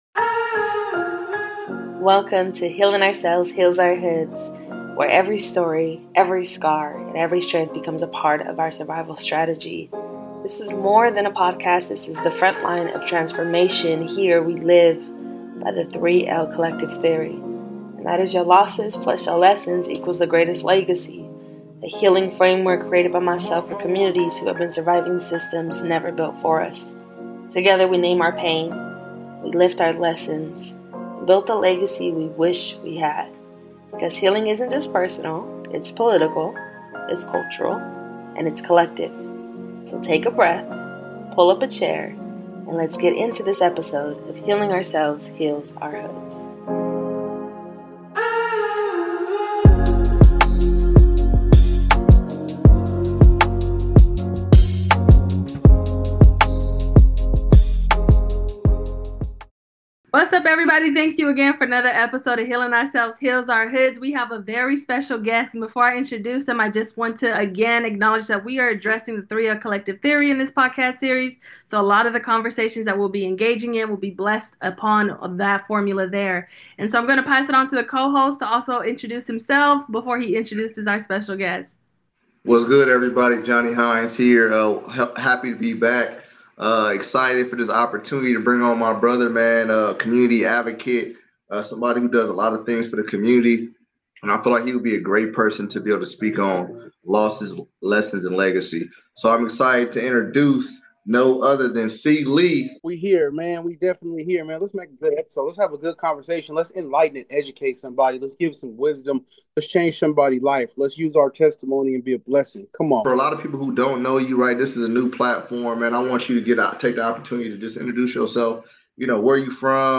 This conversation is raw, real, and rooted in hope.